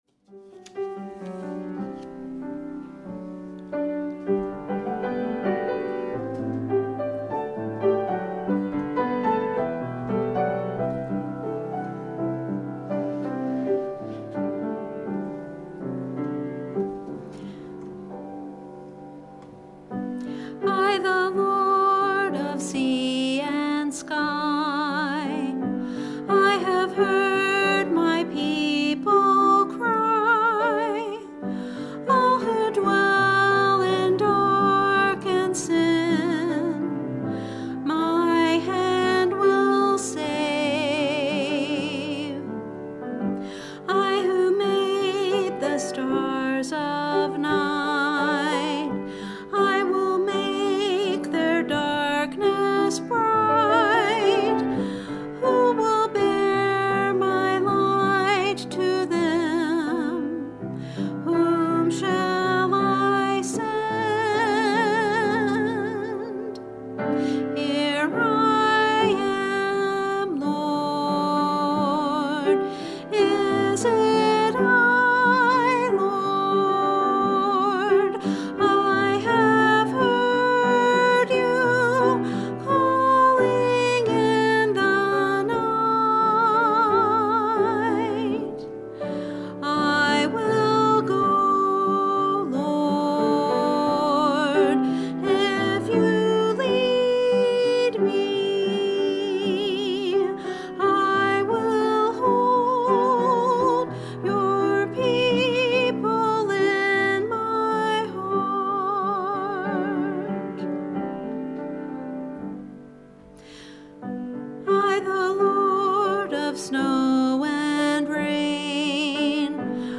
Sunday Afternoon Sermon 2024